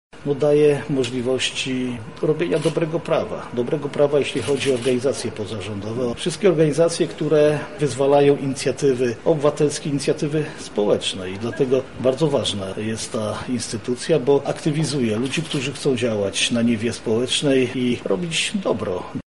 Zapytaliśmy Marszałka Województwa Lubelskiego Jarosława Stawiarskiego, dlaczego Rada Działalności Pożytku Publicznego jest istotna: